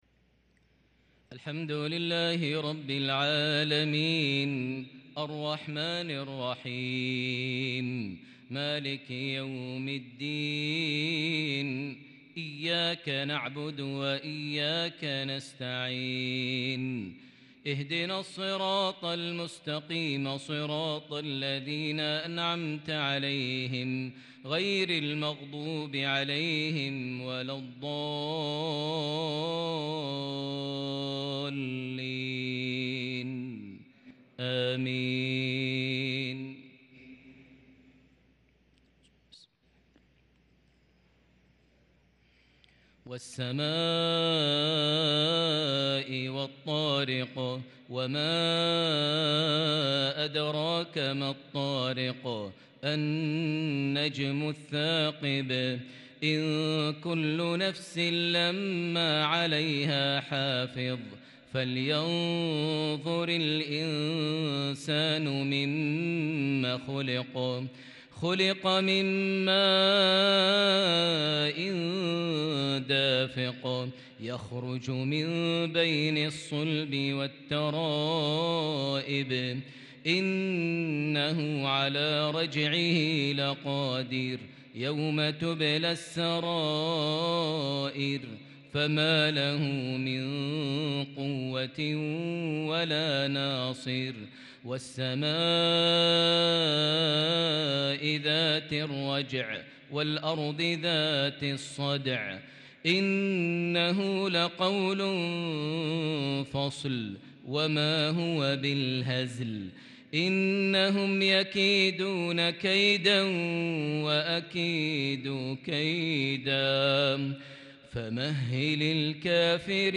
صلاة المغرب للقارئ ماهر المعيقلي 23 ذو الحجة 1443 هـ
تِلَاوَات الْحَرَمَيْن .